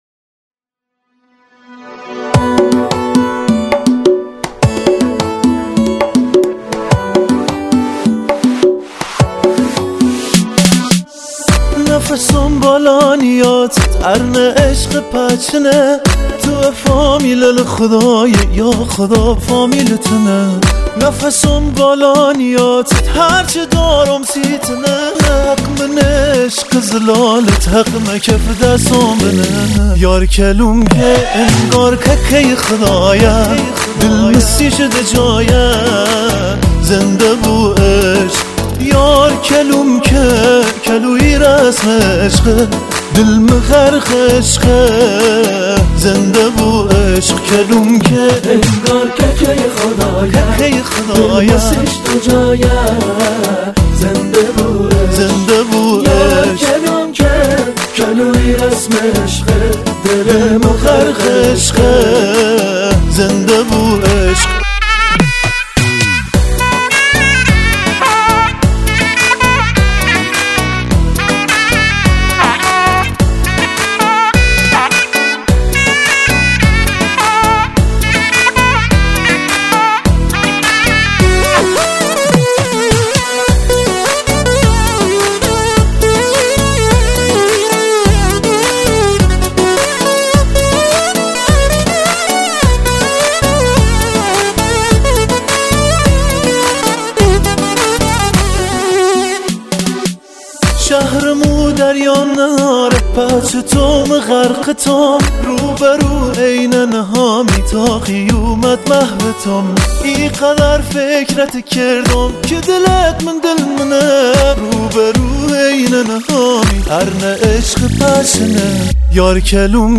Download Lori song